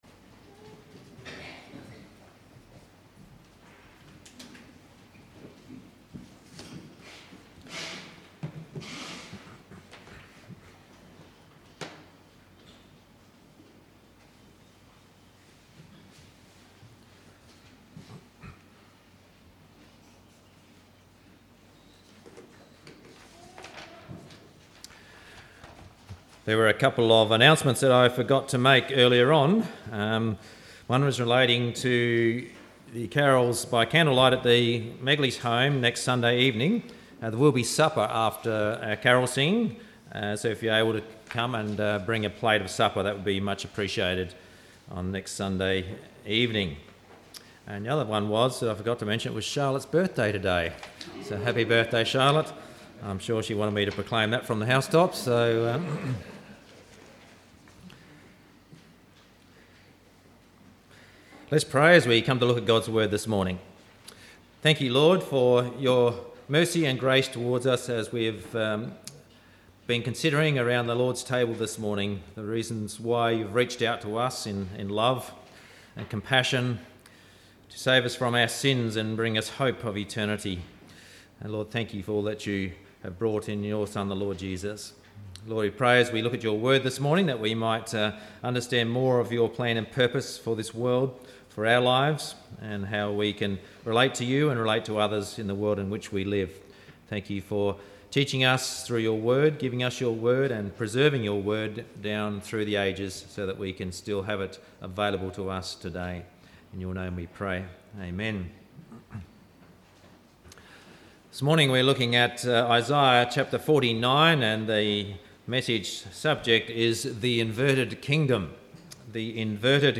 3.12.17-Sunday-Service-The-Inverted-Kingdom-Isaiah.mp3